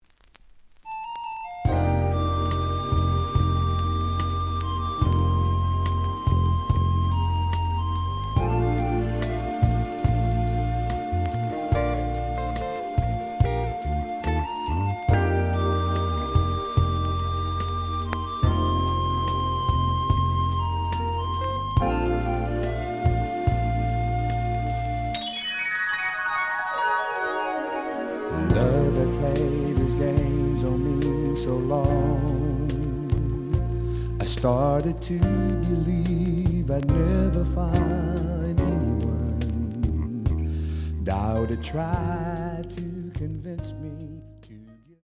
試聴は実際のレコードから録音しています。
The audio sample is recorded from the actual item.
●Genre: Soul, 80's / 90's Soul